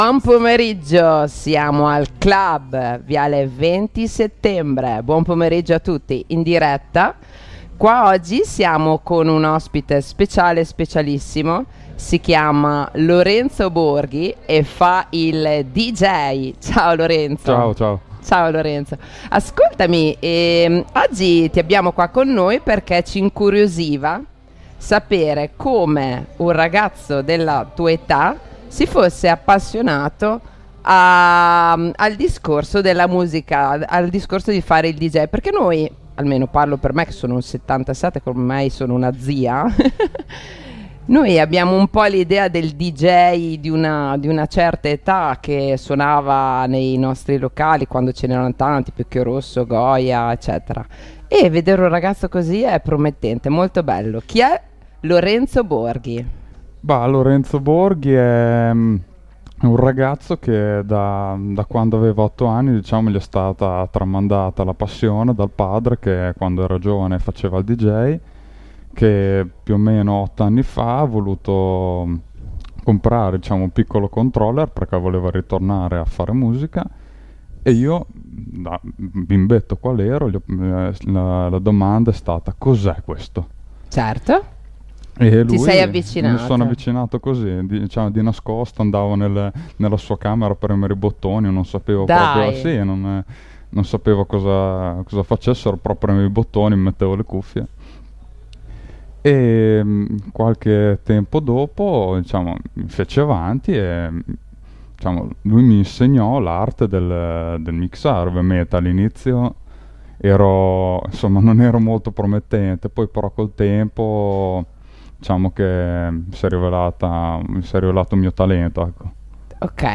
Le interviste di Linea Radio al Clhub di viale XX Settembre a Sassuolo